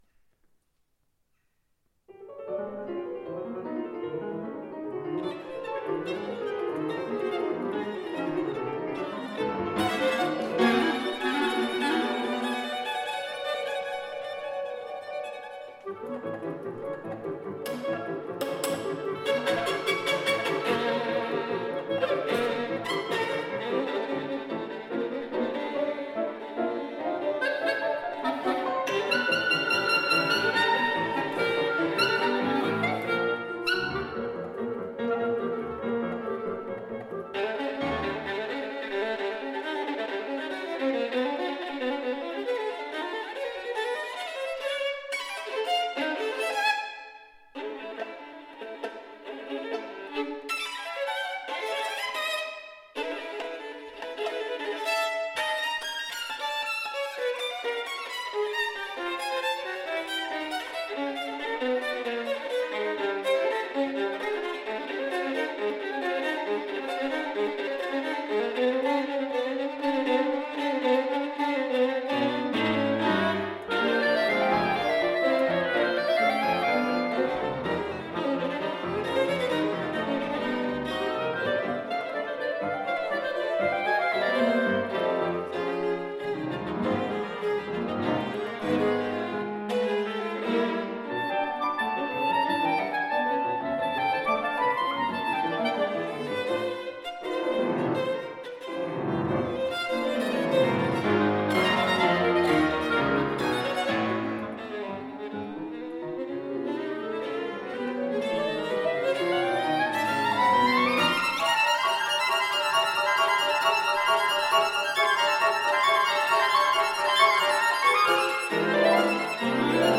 Zimro – a trio of clarinet, violin or viola and piano – was founded in 2008 whilst playing chamber music together in the beautiful surroundings of the Swaledale Festival in the Yorkshire Dales.
recorded live in All Saints Church, Long Marston
Bartok-Contrasts-3rd-mvt-extract.mp3